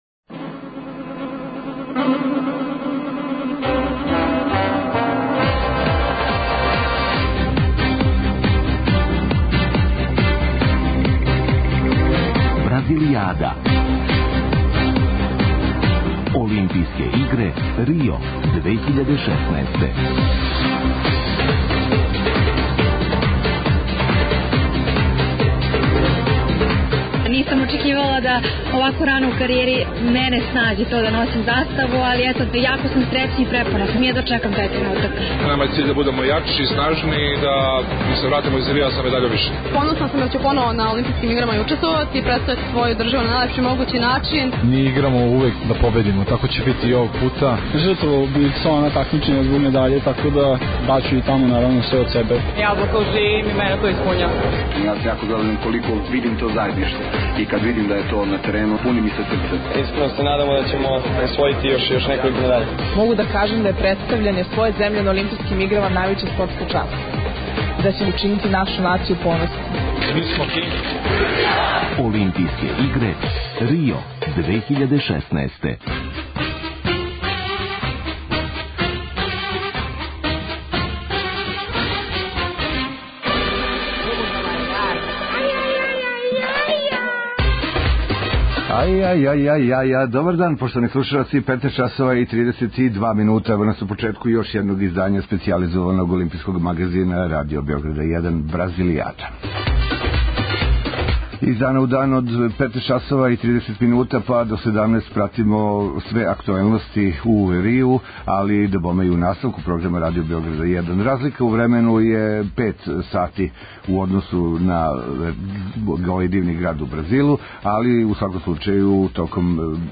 Специјална емисија која ће се емитовати током трајања Олимпијских игара у Рију. Пратимо наше спортисте који учествују на ОИ, анализирамо мечеве, уз госте у Студију 1 Радио Београда и укључења наших репортера са лица места.